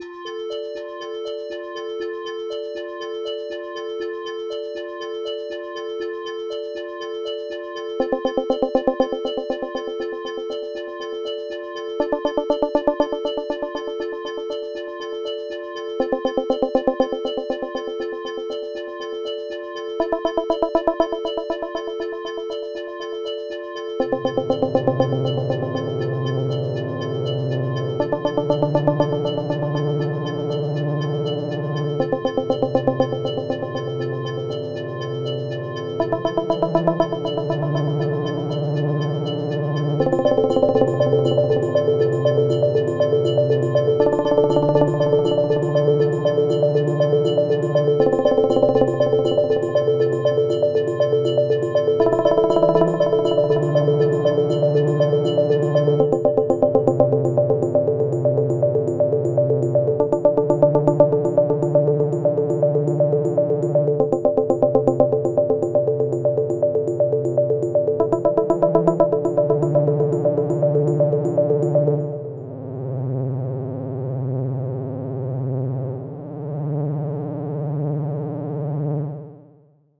Pieza de Ambient Techno
Música electrónica
tecno
melodía
repetitivo
sintetizador